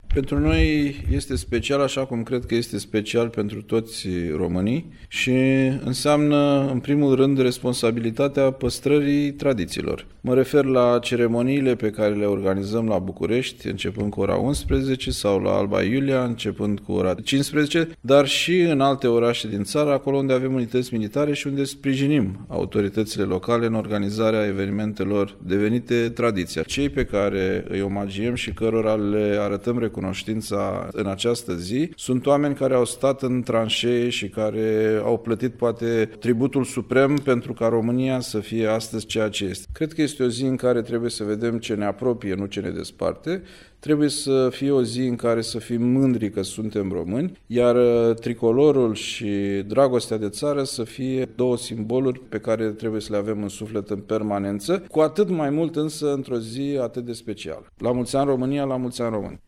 Despre cei omagiați astăzi a vorbit, pentru Radio România Actualităţi, ministrul Apărării, Angel Tîlvăr: